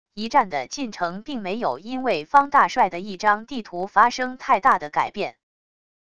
一战的进程并没有因为方大帅的一张地图发生太大的改变wav音频生成系统WAV Audio Player